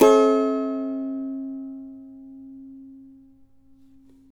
CAVA A#MJ  D.wav